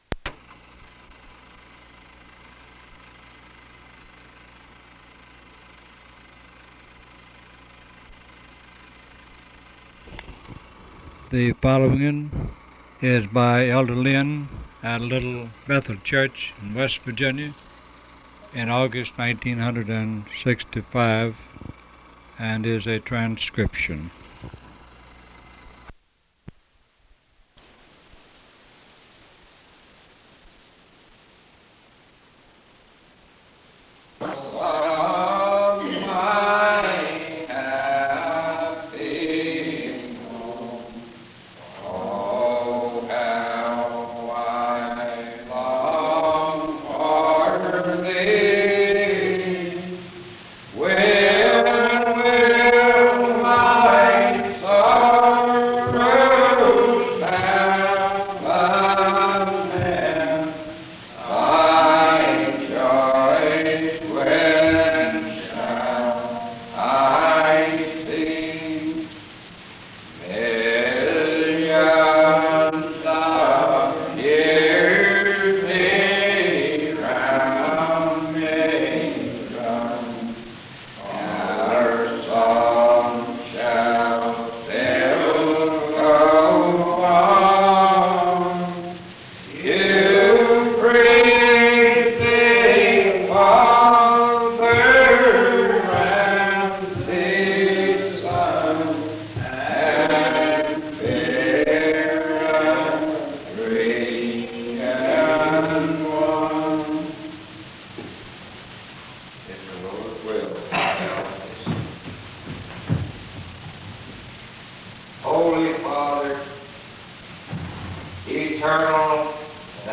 Primitive Baptists
Barbour County (W. Va.)